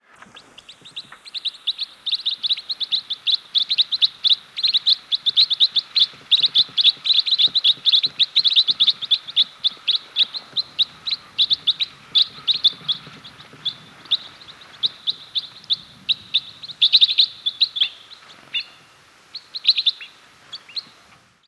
Flock sounds
8. Minnesota November 26, 2000 (WRE). Type 4.